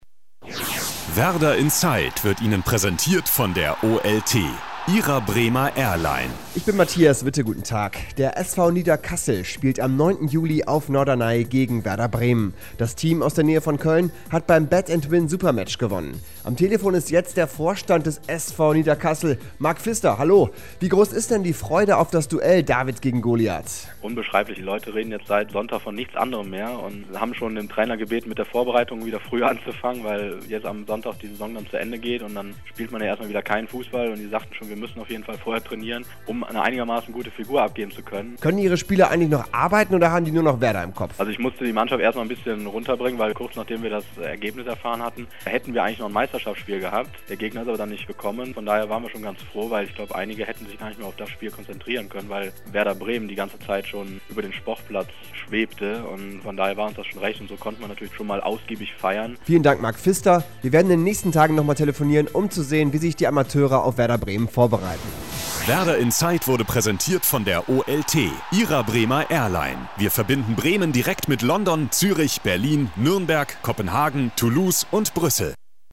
Interview in Hit-Radio-Antenne am 24.05.2005 zum Gewinn des Supermatchs von beatandwin